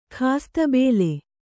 ♪ khās tabēle